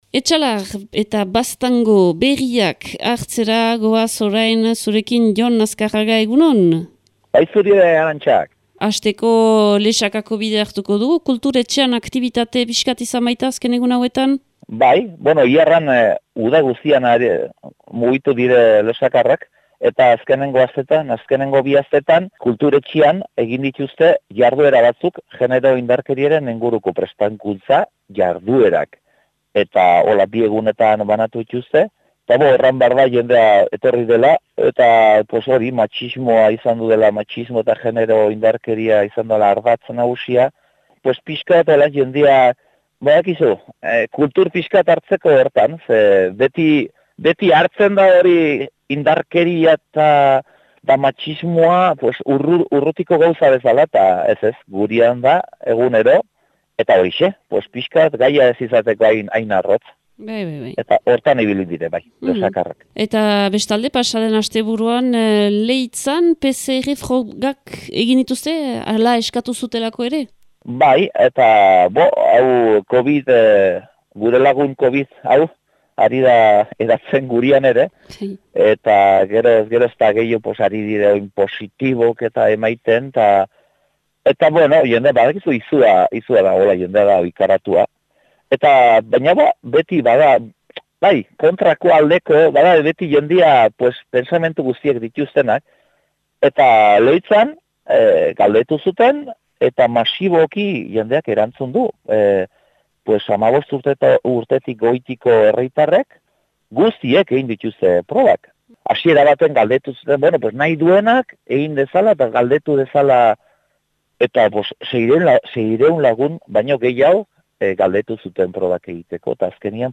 Irailaren 10eko Etxalar eta Baztango berriak